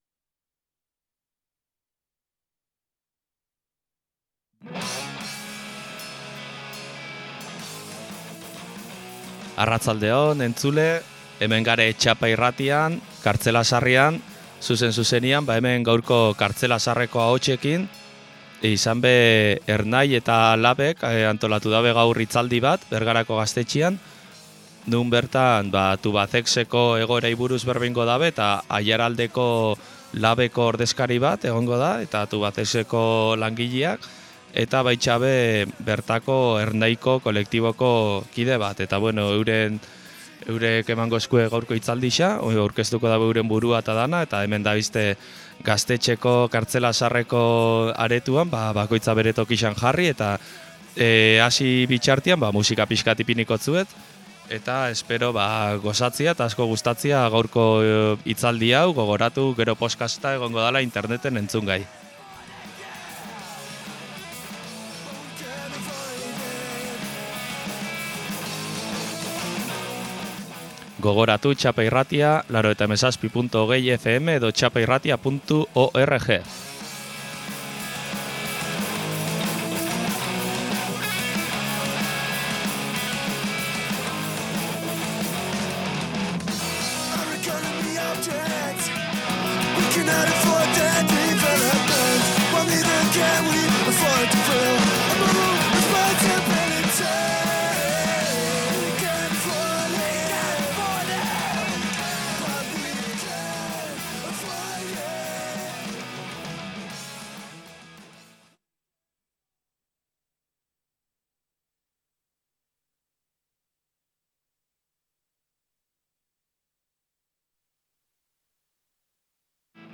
Hitzaldia-Tubazex-LAB-Ernai.mp3